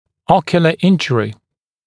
[‘ɔkjələ ‘ɪnʤərɪ][‘окйэлэ ‘инджэри]травма глаза (глаз)